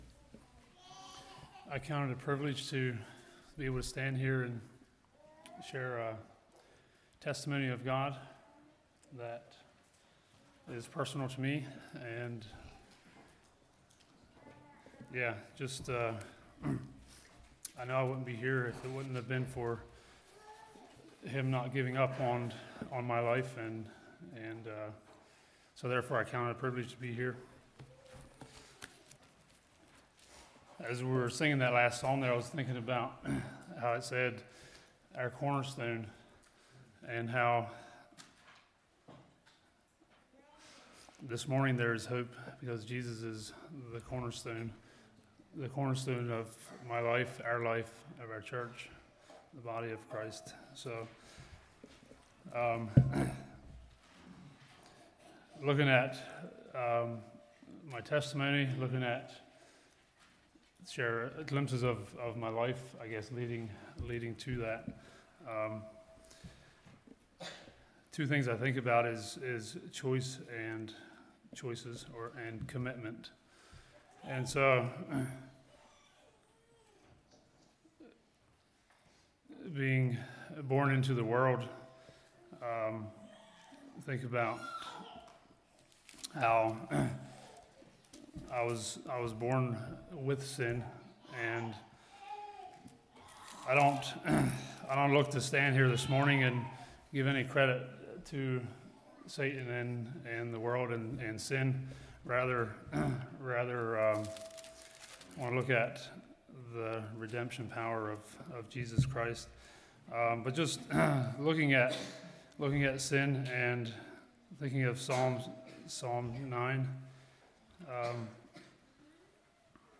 shares his personal testimony.